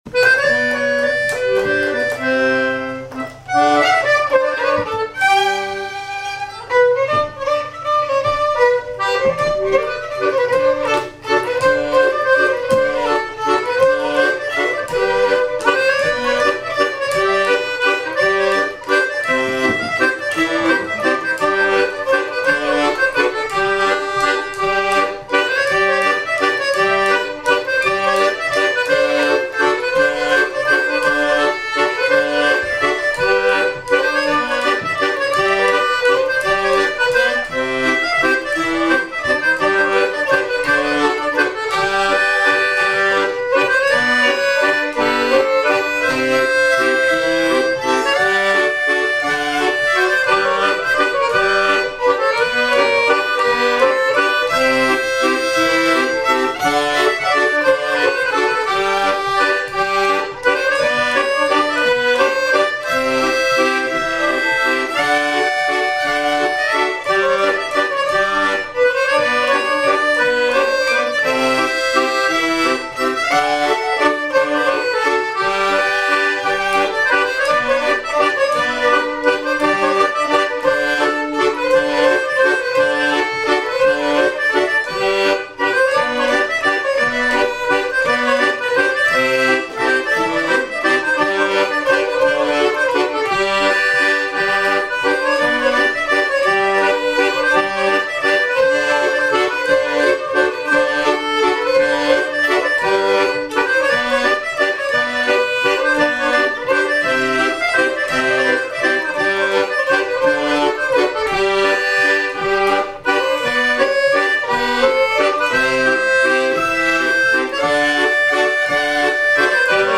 Mémoires et Patrimoines vivants - RaddO est une base de données d'archives iconographiques et sonores.
danse : mazurka-valse
Répertoire de bal au violon et accordéon
Pièce musicale inédite